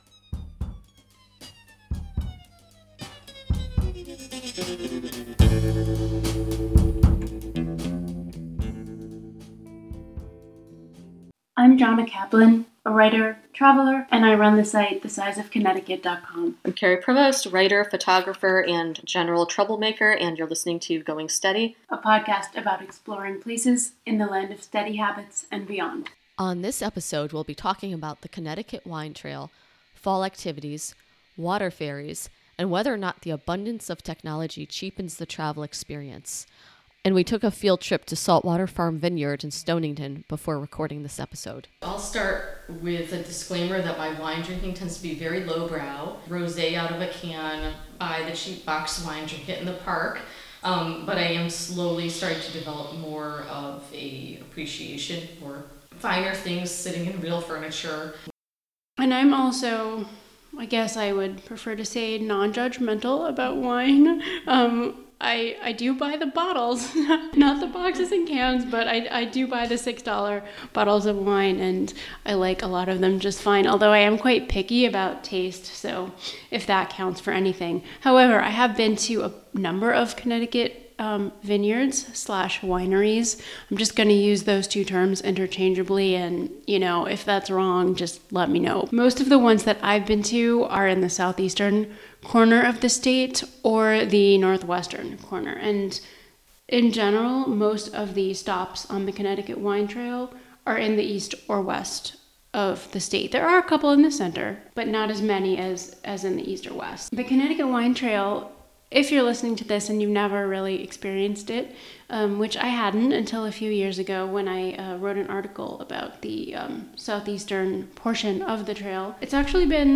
Note: This podcast contains occasional, relatively lightweight curse-words.